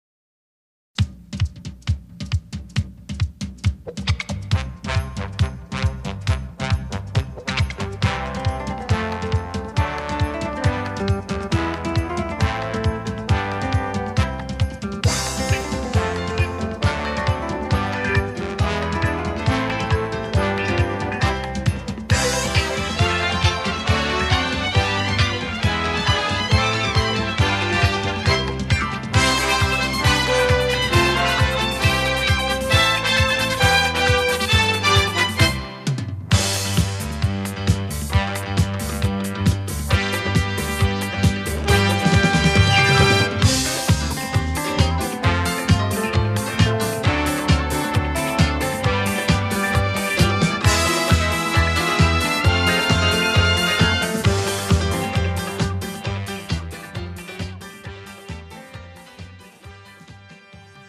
MR 고음질 반주